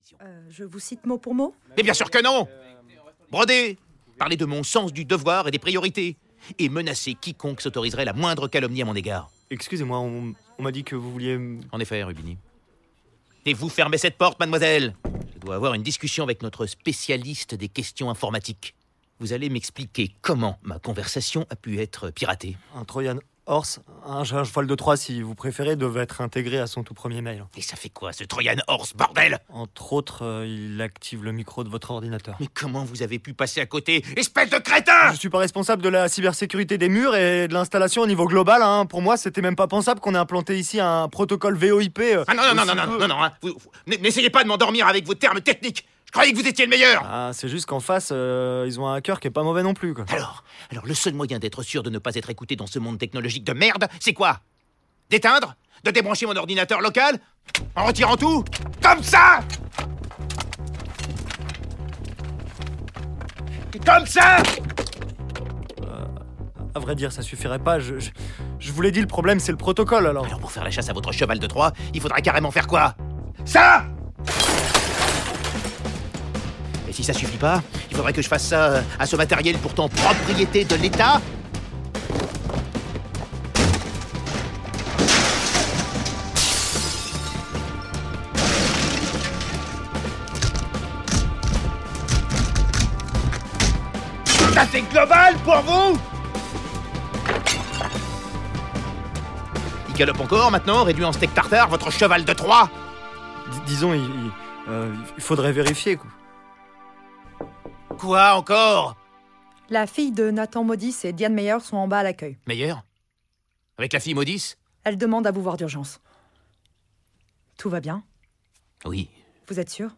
Impact, la série audio - Episode 5